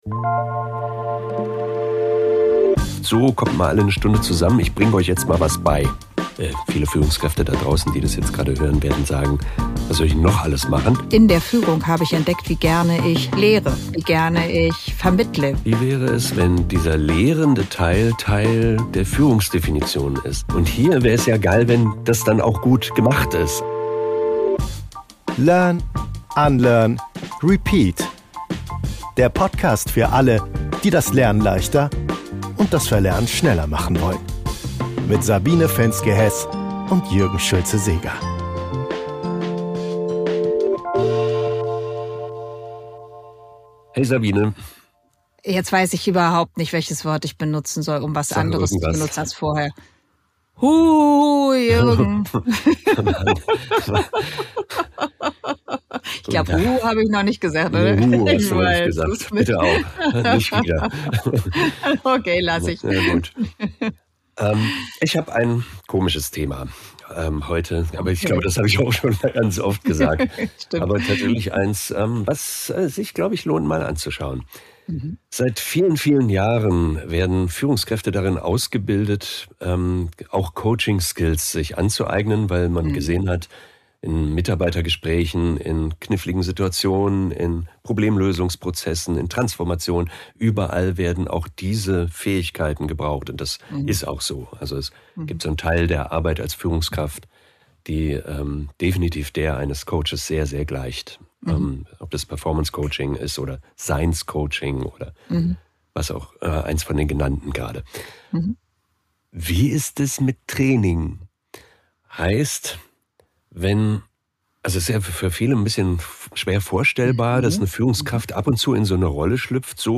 Auf jeden Fall ein Gespräch - mit Zündstoff.